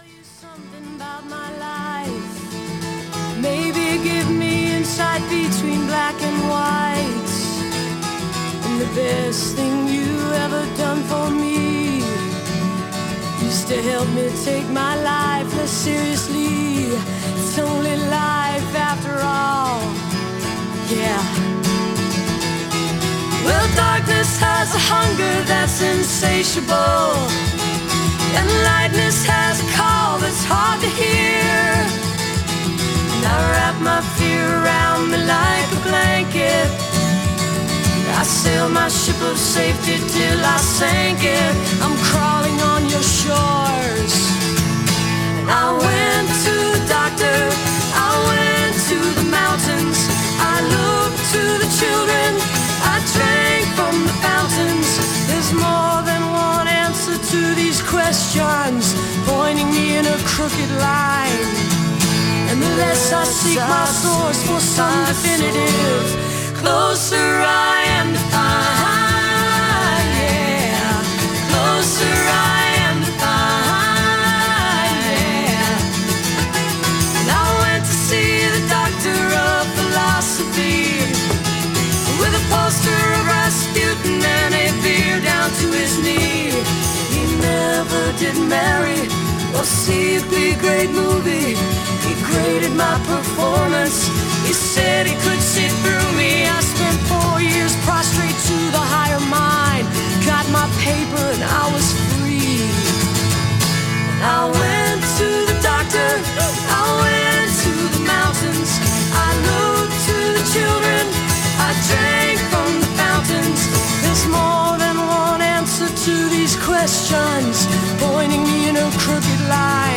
captured from webcast
album version